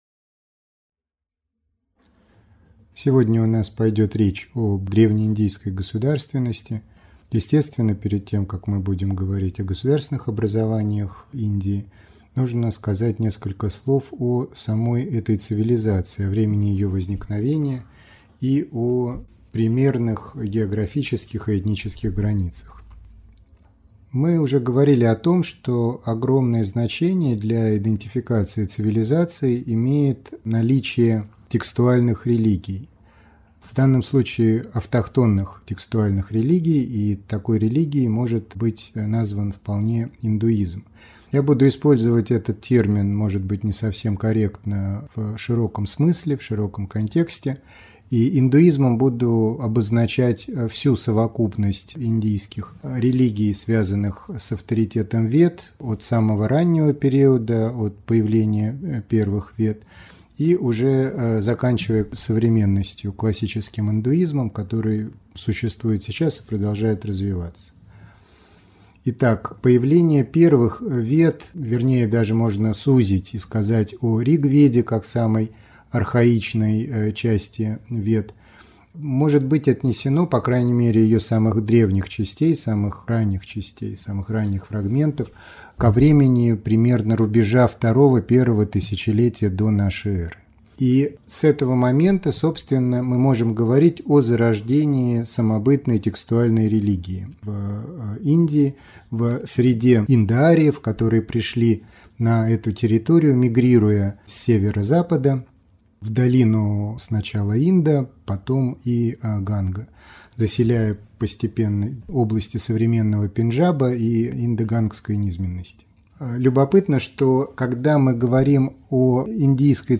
Аудиокнига Индия. Царство Маурья и ненасилие | Библиотека аудиокниг